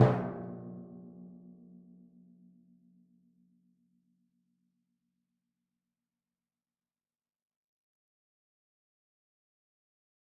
Timpani2_Hit_v4_rr2_Sum.mp3